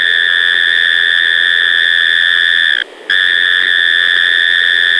Name : Dark Night Mole Cricket - Gryllotalpa monanka, male
Habits : sings after dark for half an hour